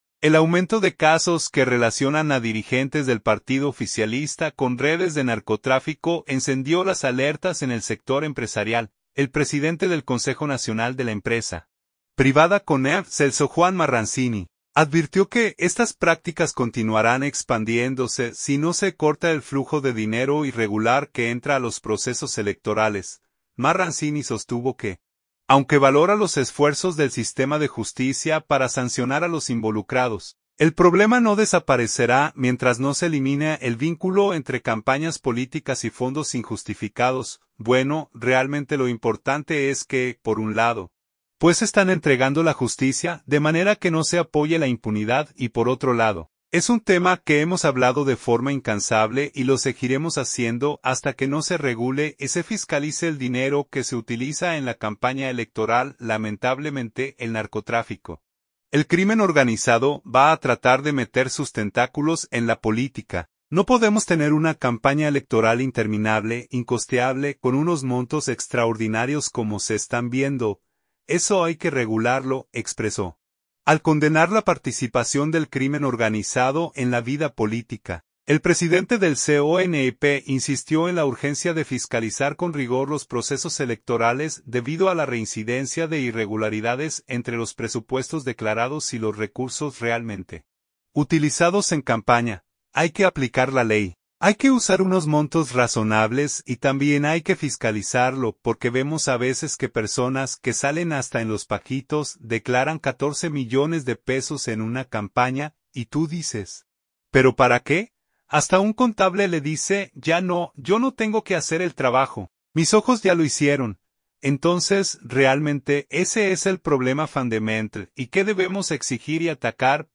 El líder empresarial fue entrevistado durante el lanzamiento de Conducta Empresarial Responsable (CER), una plataforma digital diseñada para impulsar prácticas éticas, transparentes y sostenibles en las empresas.